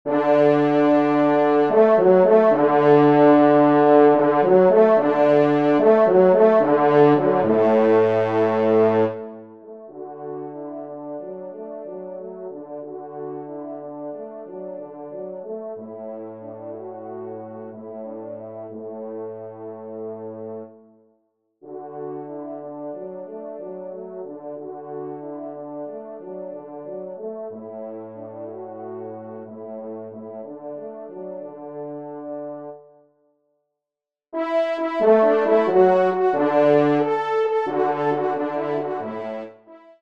Pupitre     4°Trompe